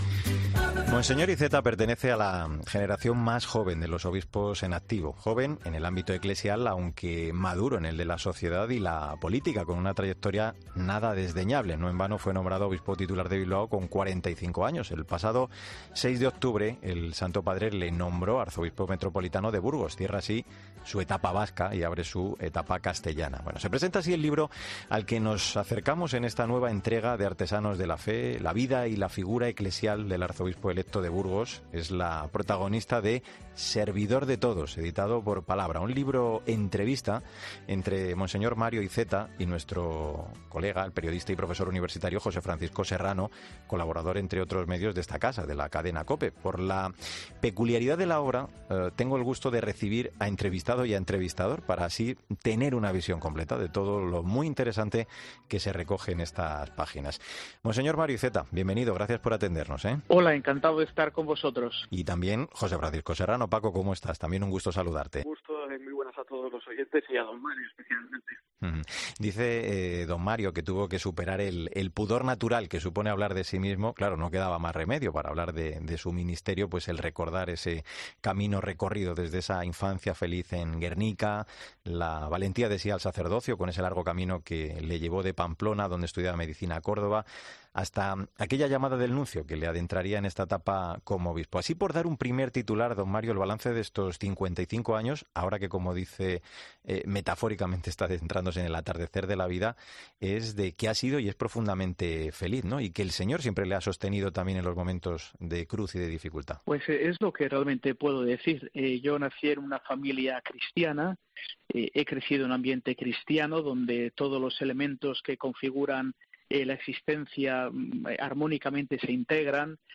Una obra en la que vemos reflejado a un obispo que representa a una nueva generación con otra mentalidad, otra perspectiva, otra forma de argumentar y de discurso que se refleja en estas páginas. En esta entrevista no se elude ni se deja fuera de esta charla ningún tema, ni siquiera de esos que podrían considerarse complicados.